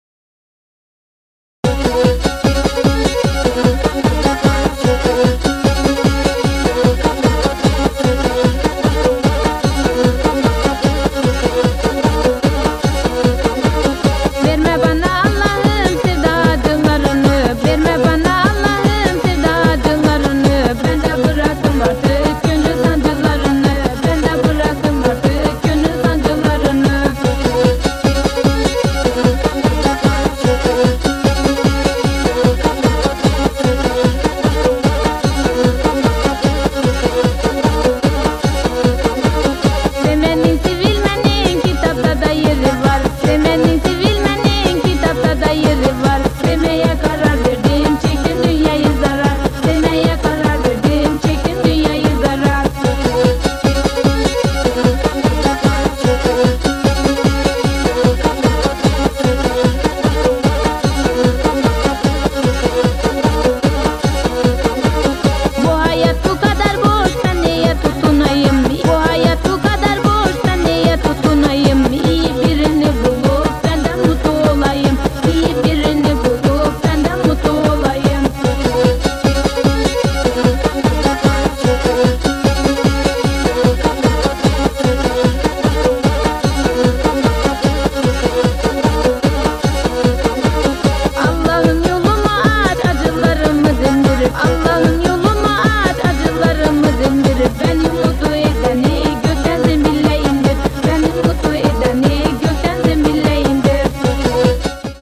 トルコの伝統的なダンス“ホロン”！ケマンチェとダウルで繰り広げられる変拍子を孕んだミニマルなビートが◎！